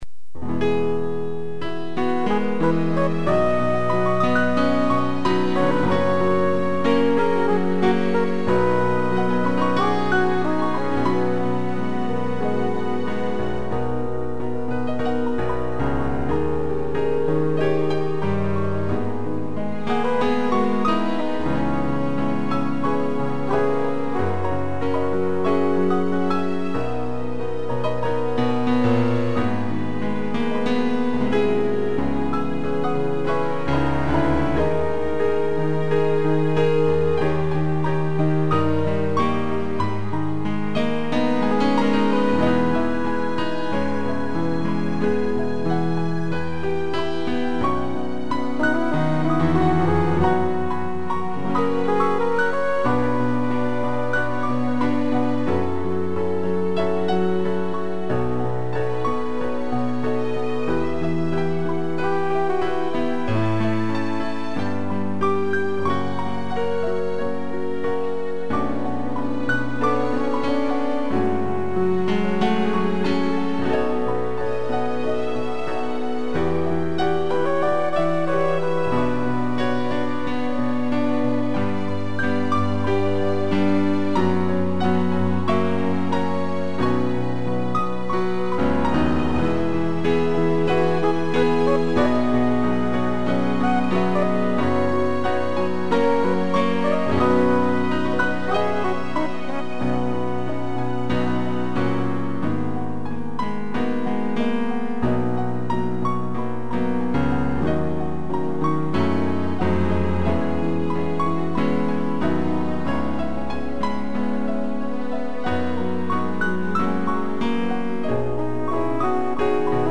2 pianos, flugelhorn e strings
(instrumental)